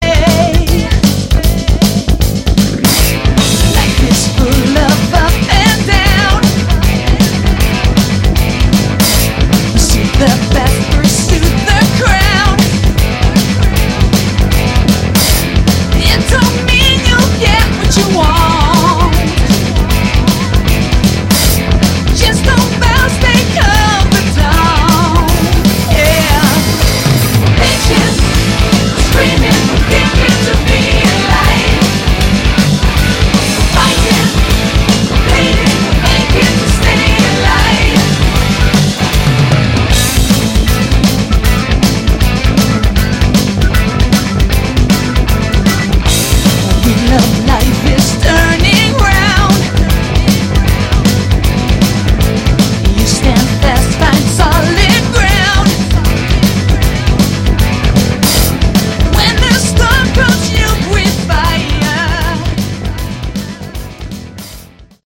Category: Melodic Rock
vocals
bass
guitar
drums
keyboards